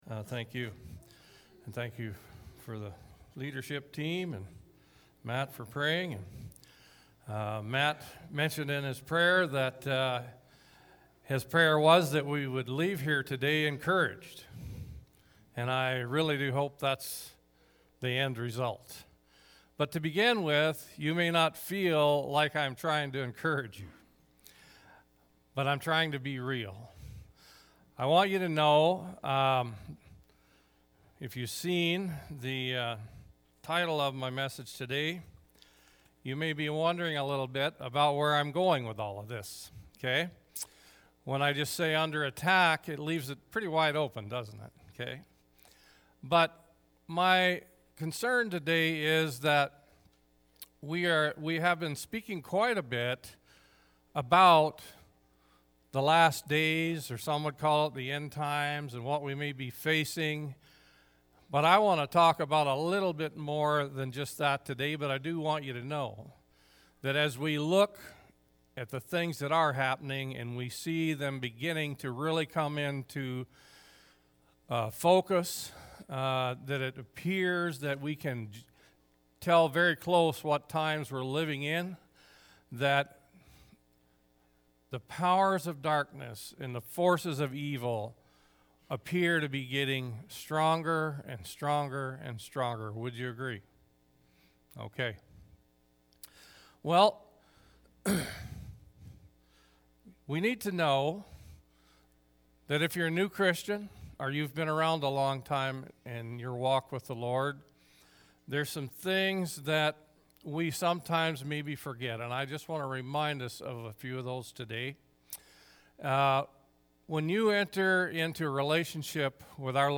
March-14-sermon.mp3